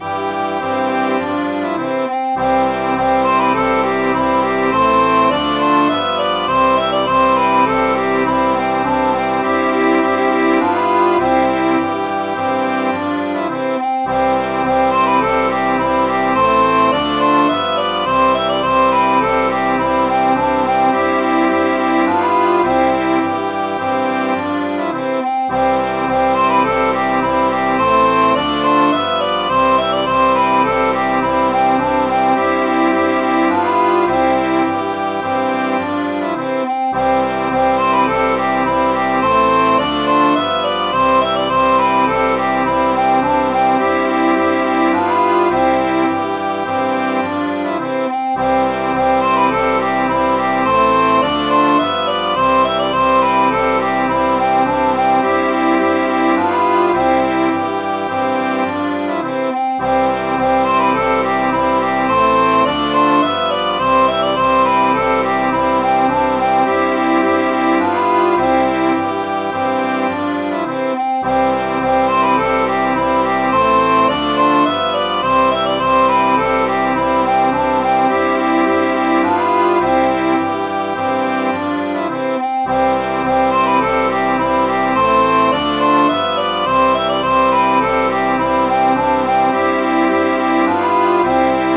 Midi File, Lyrics and Information to Sally In Our Alley